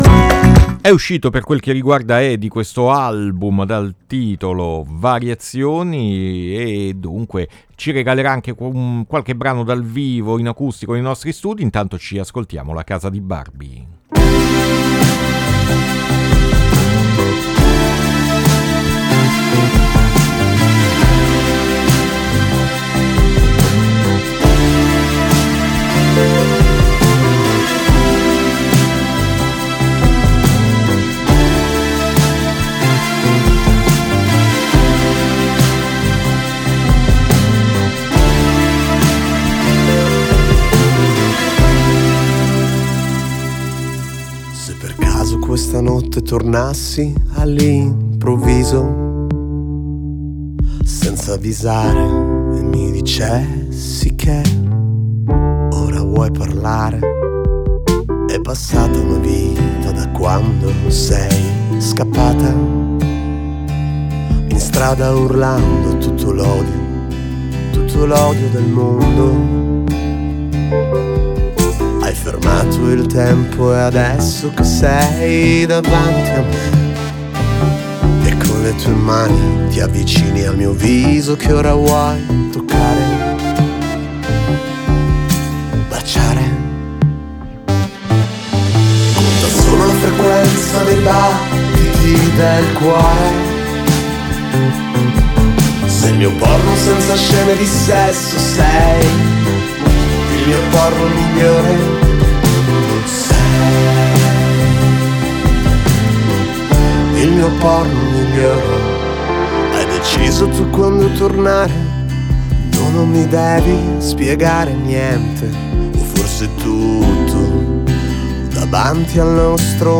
Interviste Mercoledì Morning